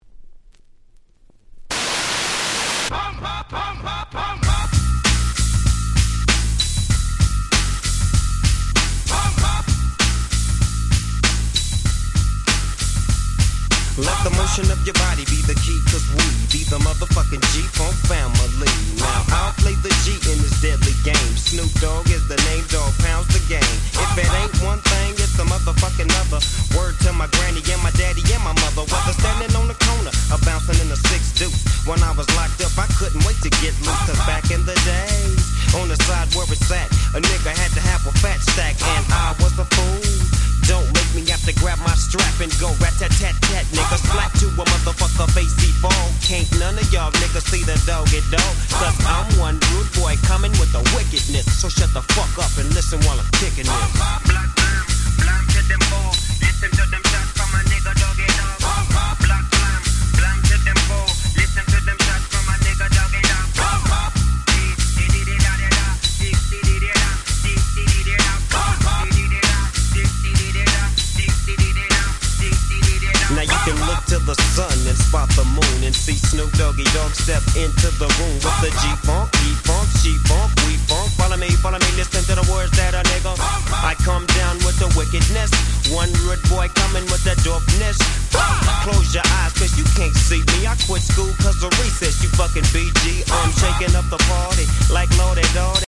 94' Very Nice West Coast Hip Hop !!